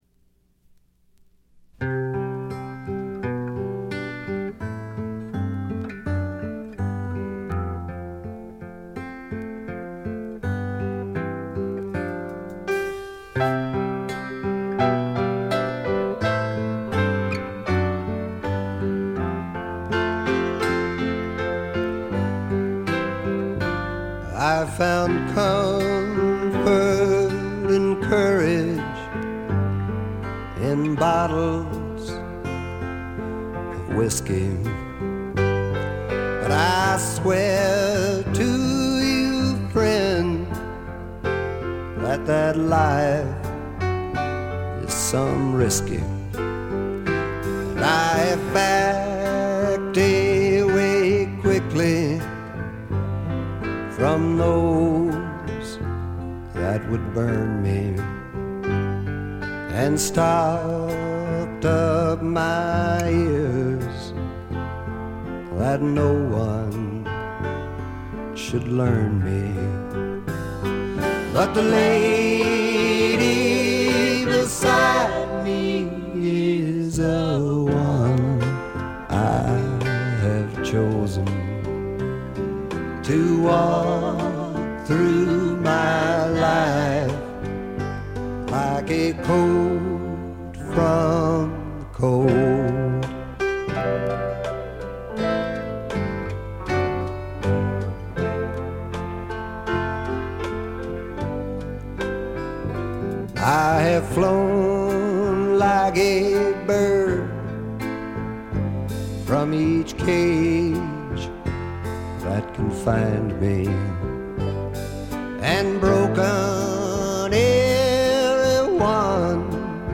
ほとんどノイズ感無し。
朴訥な歌い方なのに声に物凄い深さがある感じ。
試聴曲は現品からの取り込み音源です。
Guitar, Vocals